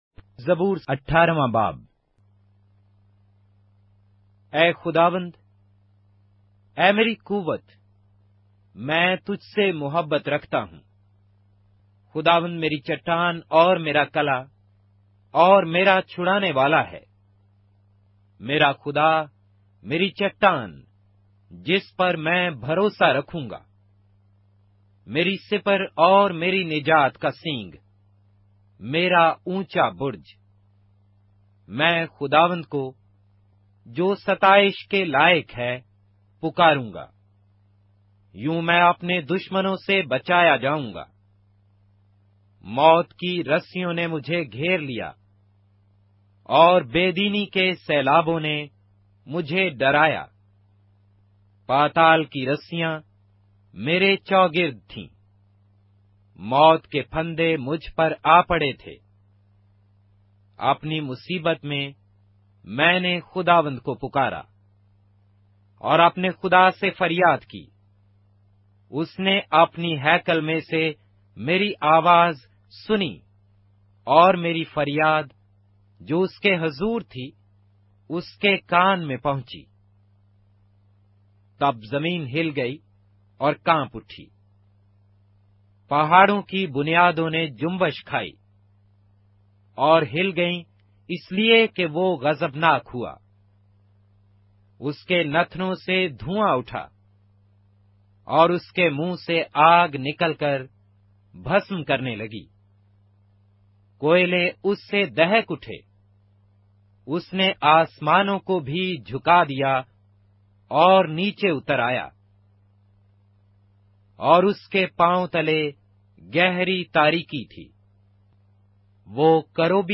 اردو بائبل کے باب - آڈیو روایت کے ساتھ - Psalms, chapter 18 of the Holy Bible in Urdu